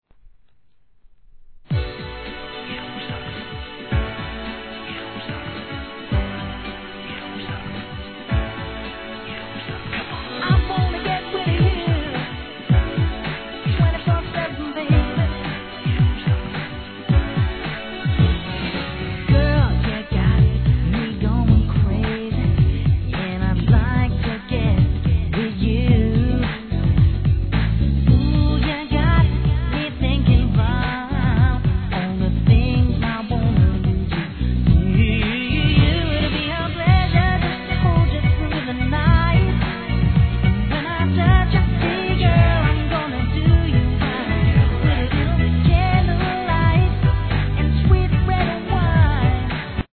HIP HOP/R&B
UK出身のシンガー。アップテンポなナンバーでこちらもPOPな仕上がり!